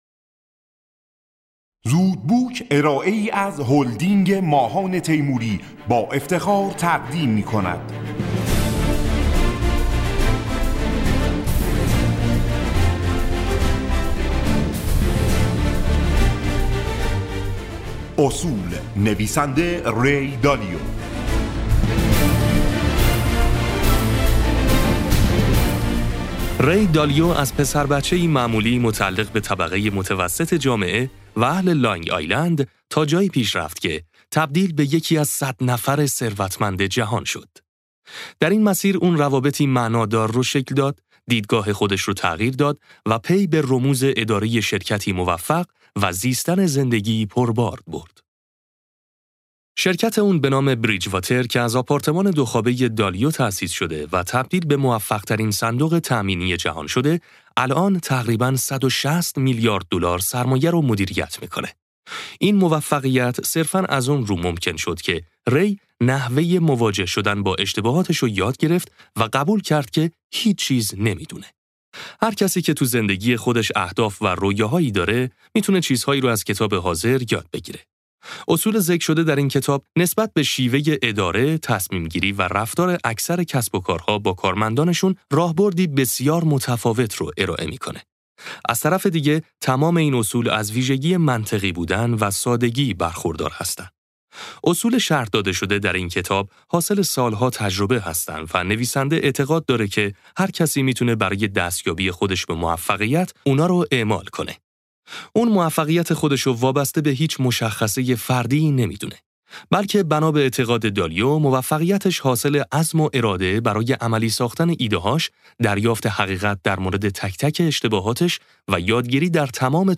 خلاصه کتاب صوتی اصول